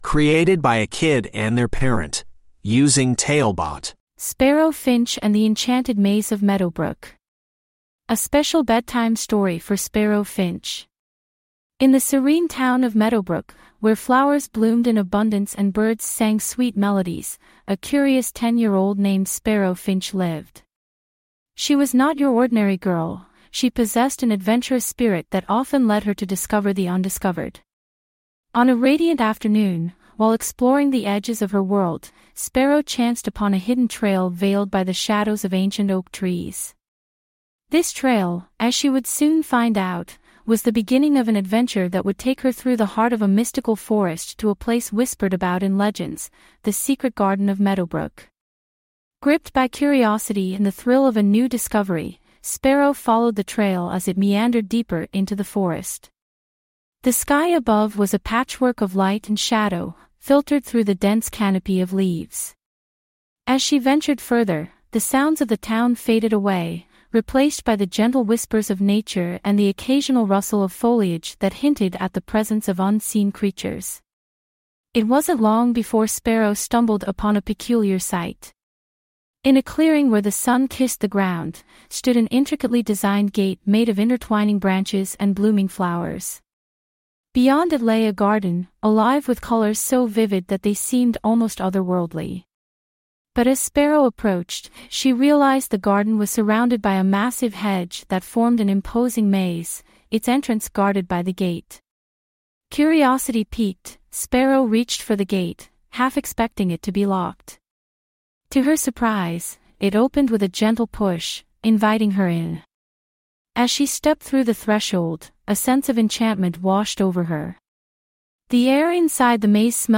TaleBot AI Storyteller